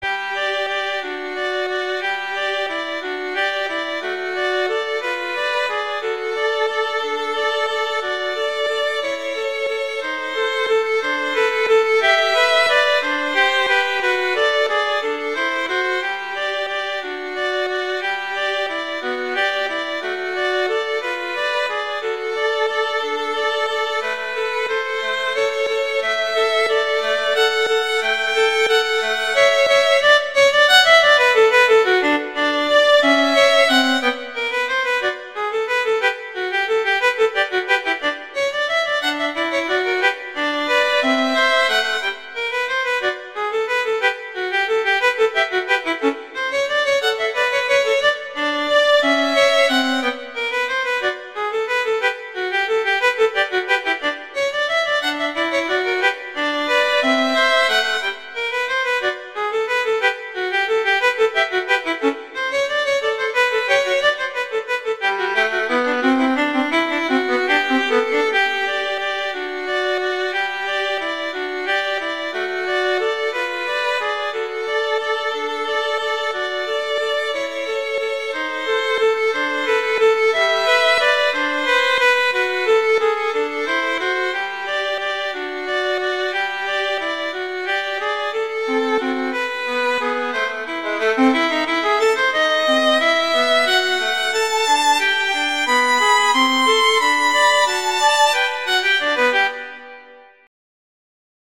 * Exclusive Arrangement *
classical, children
G major
♩=180 BPM (real metronome 176 BPM)